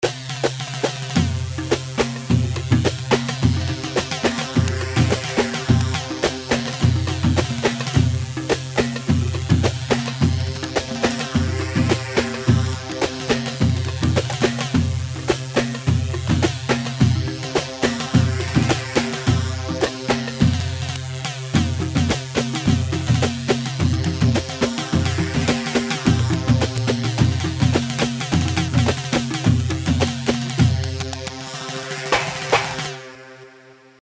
Hewa 4/4 106 هيوا